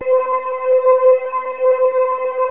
机器人声乐 " 合成歌声音符C 变奏10
Tag: 歌唱 语音 合成器 数字 语音编码器 请注意 puppycat 机器人 电子的 合成的 自动调谐 C